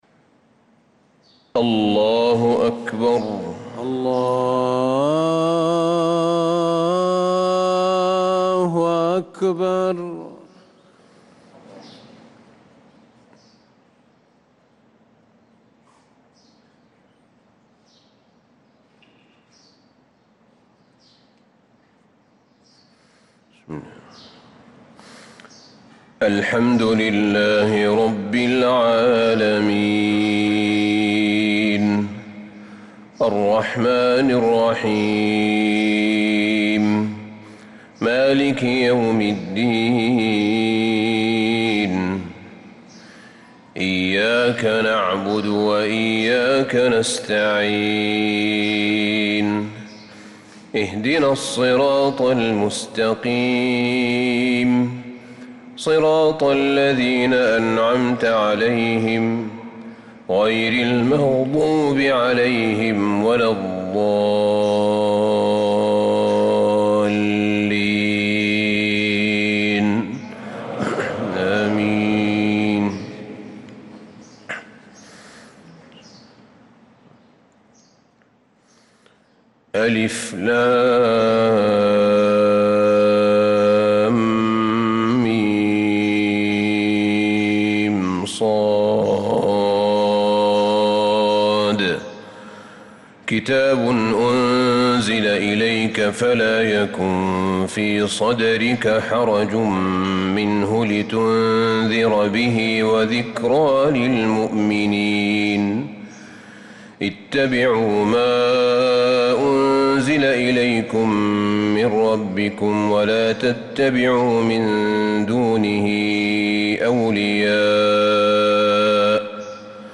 صلاة الفجر للقارئ أحمد بن طالب حميد 3 صفر 1446 هـ
تِلَاوَات الْحَرَمَيْن .